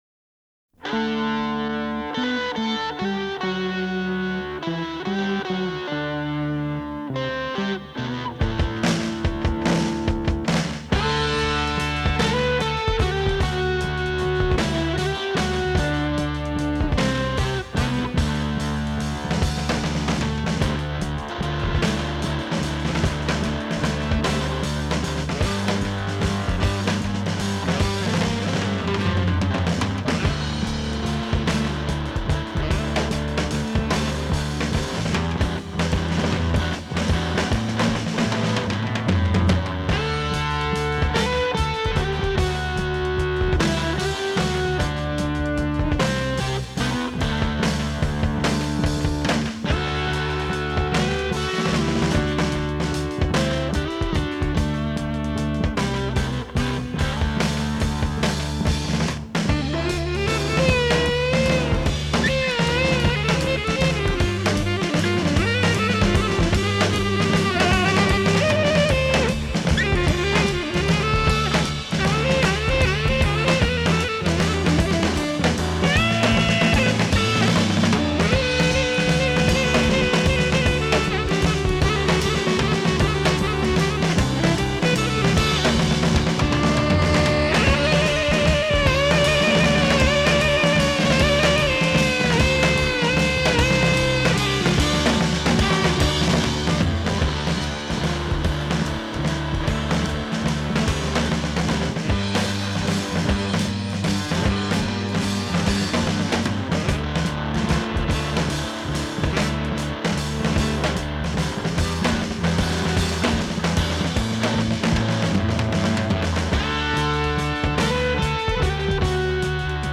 concept album